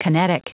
Prononciation du mot kinetic en anglais (fichier audio)
Prononciation du mot : kinetic